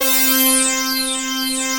BIG LEADC4-L.wav